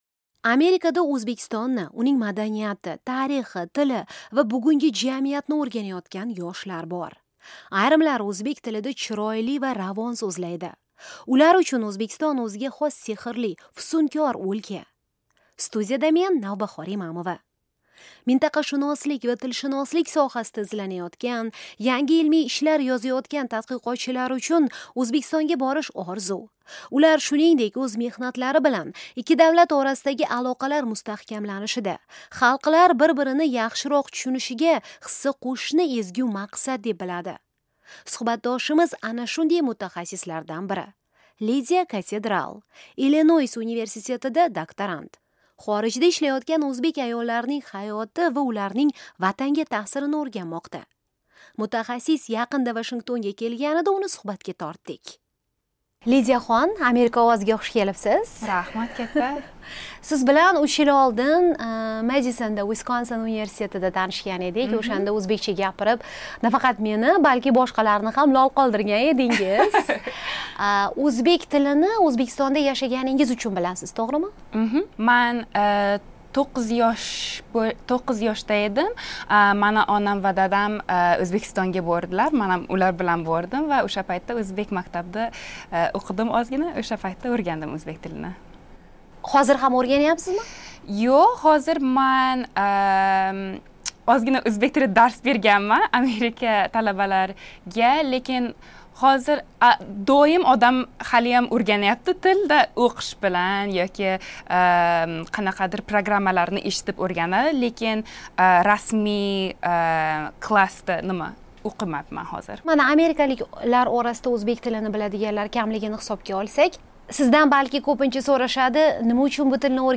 Amerikalik yosh olima bilan o'zbekcha suhbat - AQSh/O'zbekiston